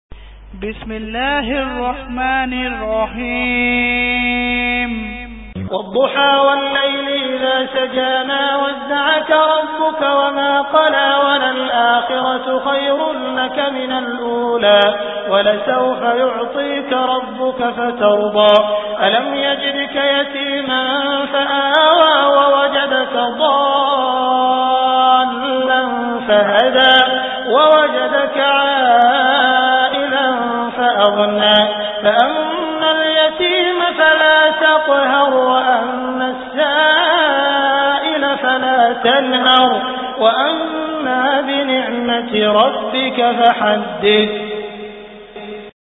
Surah Ad Duha Beautiful Recitation MP3 Download By Abdul Rahman Al Sudais in best audio quality.